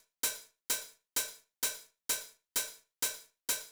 INT Beat - Mix 14.wav